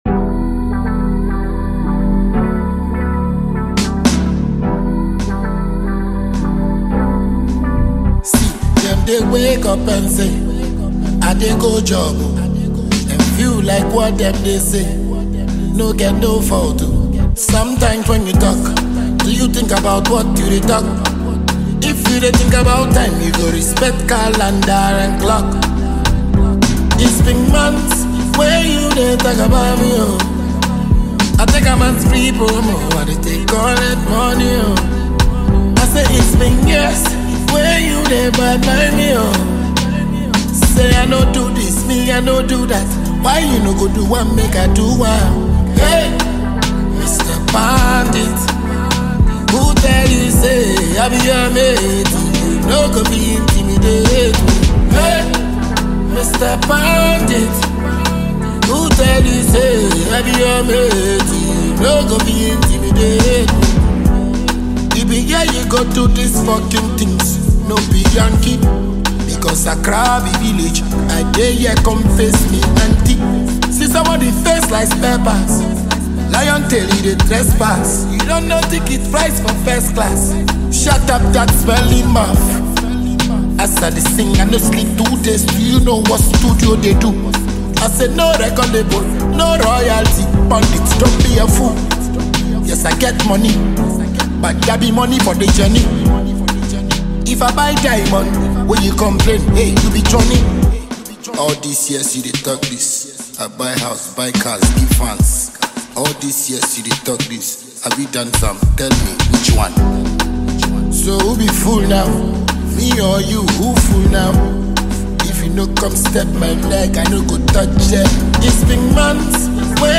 Ghanaian dancehall artist